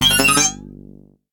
06416 scifi code access ding
Category ⚡ Sound Effects
access alert beeps bleep code coding enter scifi sound effect free sound royalty free Sound Effects